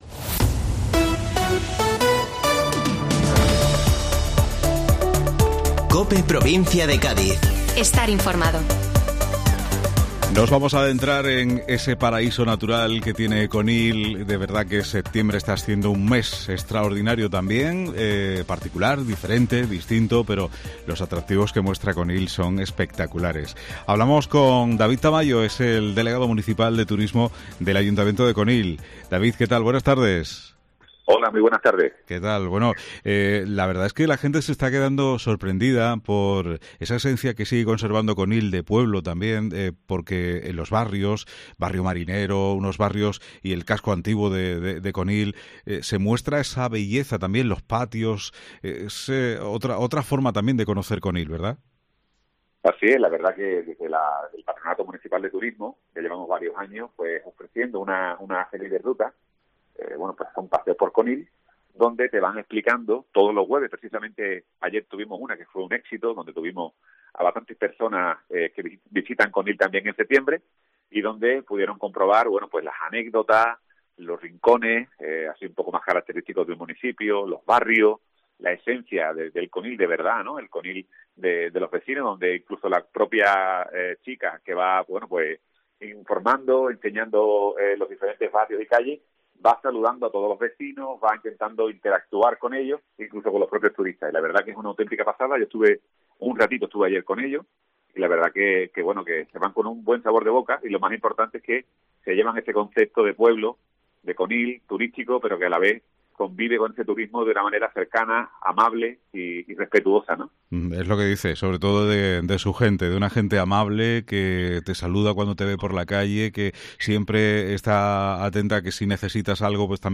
David Tamayo, Deleg Turismo Ayuntamiento de Conil - Rutas turísticas y jornadas gastronómicas